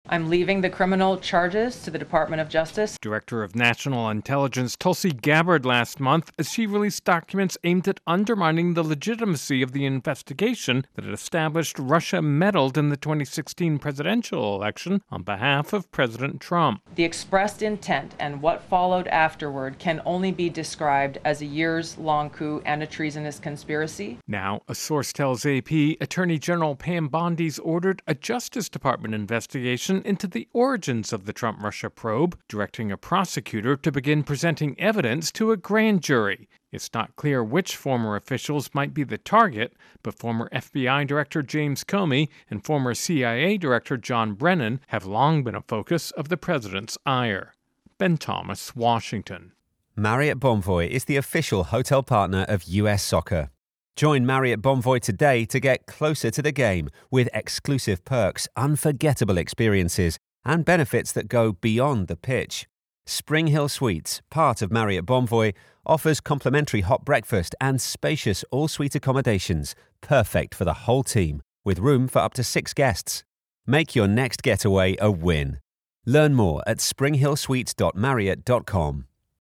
((opens with actuality))